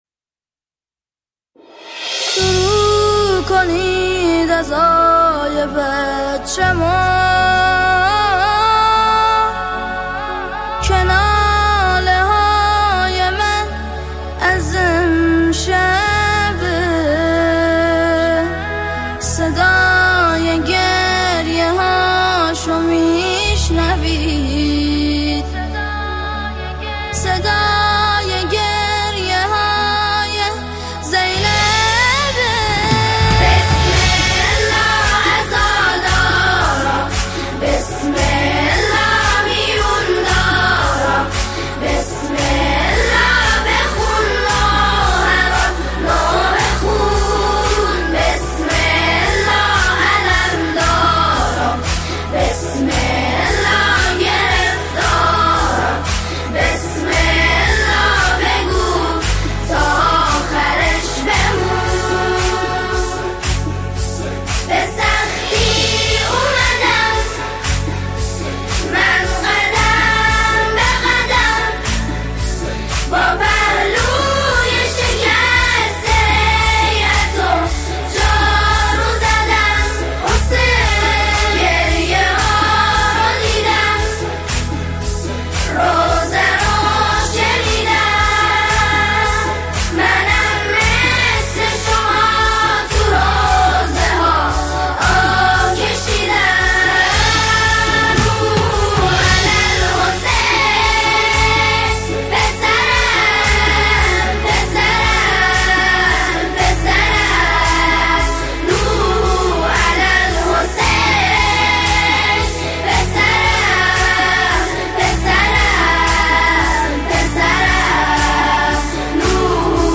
نماهنگ جدید